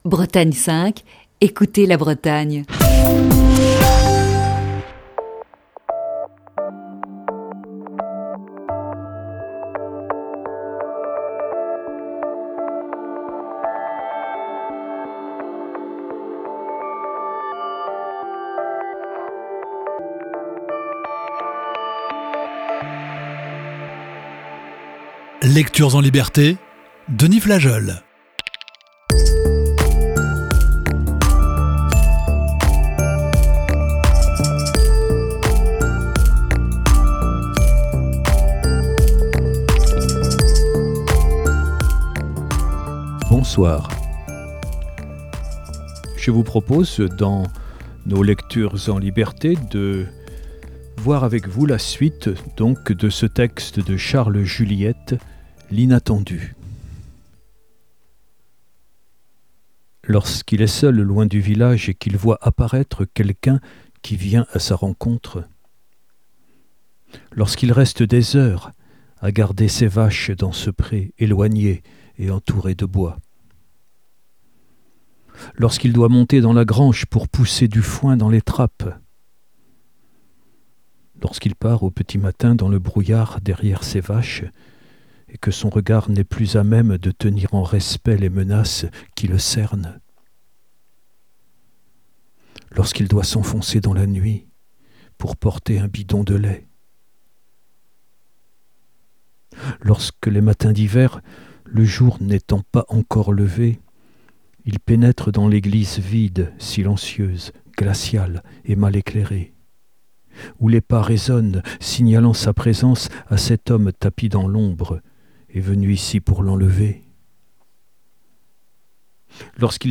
Émission du 7 janvier 2021.